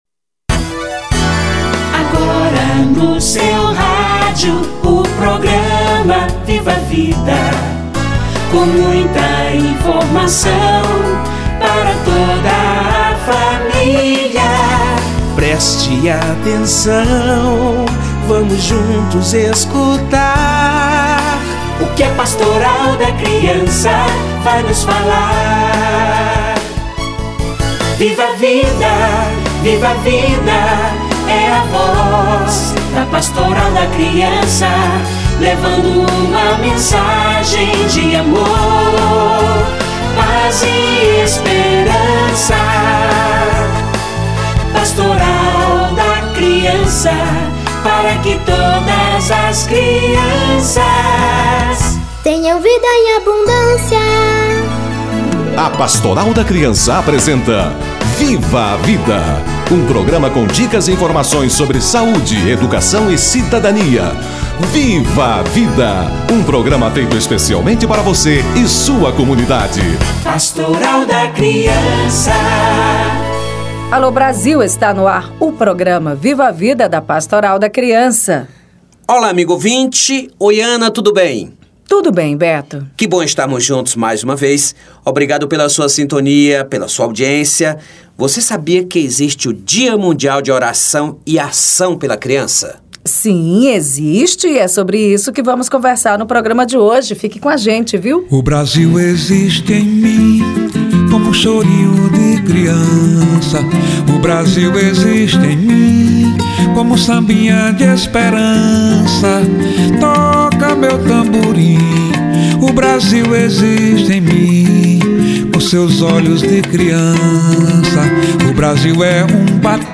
Oração e Ação pela Criança - Entrevista